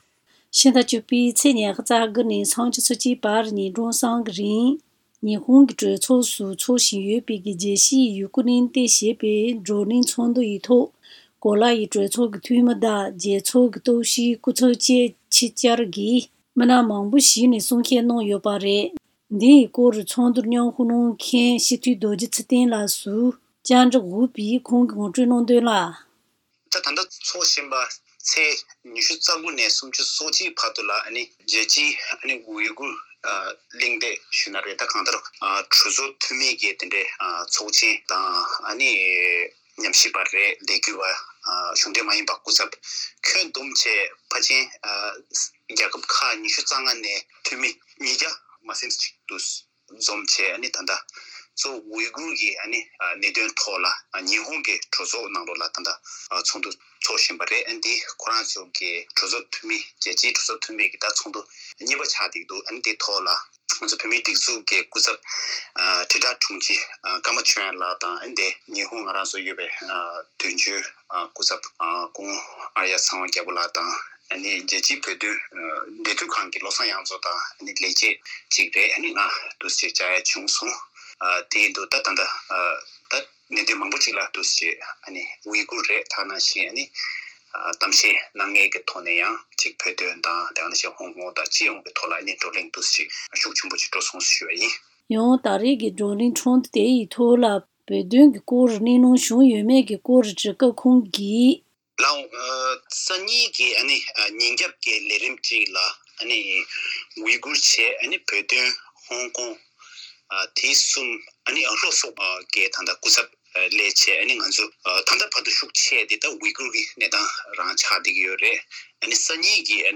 བགྲོ་གླེང་ཚོགས་འདུར་མཉམ་ཞུགས་གནང་མཁན་སྤྱི་འཐུས་རྡོ་རྗེ་ཚེ་བརྟན་ལགས་སུ་བཅའ་འདྲི་དང་གནས་ཚུལ་ཕྱོགས་སྒྲིག་བྱས་པར་གསན་རོགས་ཞུ།